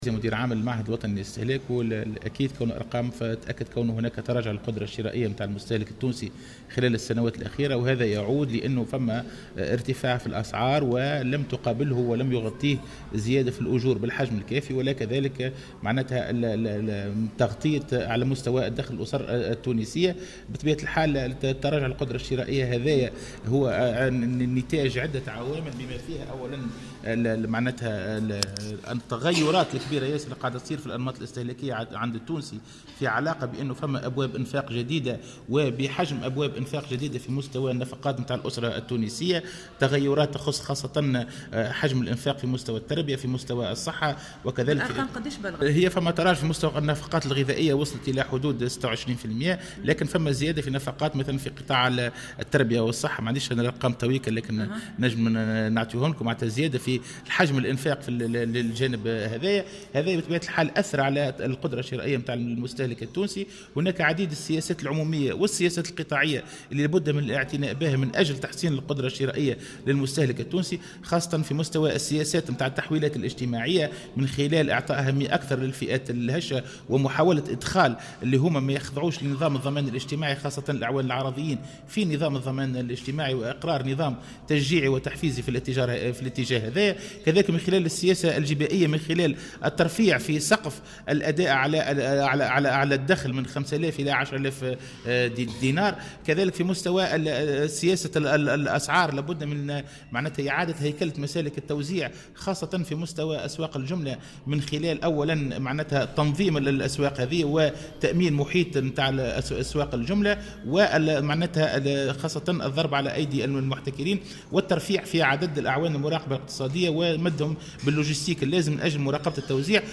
ولاحظ في تصريح لمراسلة "الجوهرة اف ام" أن عدة عوامل ساهمت أيضا في هذه الوضعية بينها تغير نمط الاستهلاك على غرار الزيادة في حجم الانفاق على مستوى التربية والصحة.